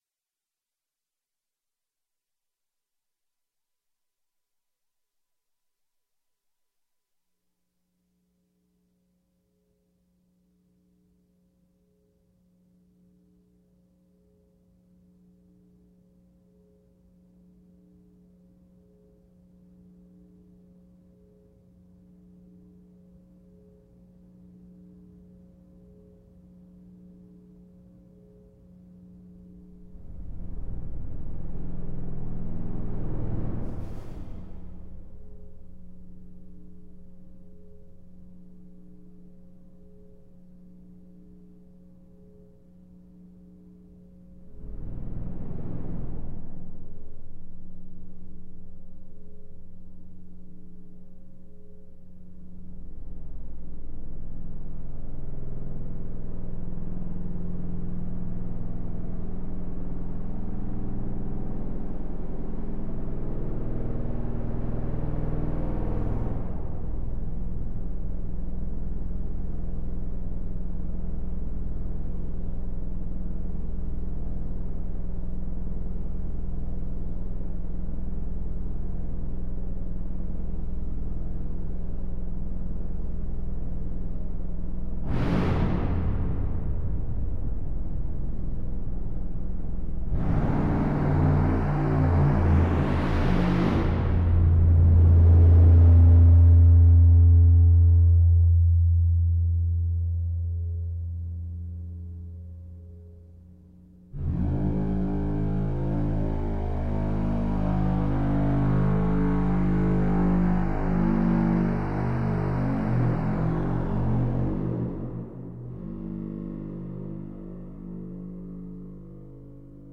This piece is the result of an improvisation I performed on a Behringer 1204FX hardware mixer.
There is no additional material in the recording, nor any post-processing aside from normalization.
Typically used for audio routing and recording, any audio mixer can be turned into a feedback instrument when routed properly. This particular mixer has built-in digital signal processing which allows for more sonic possibilities and richer timbres.